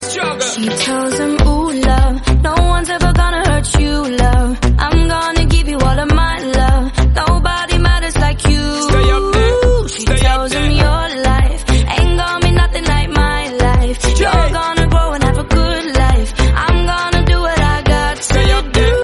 With profound lyrics and catchy melody
modern pop music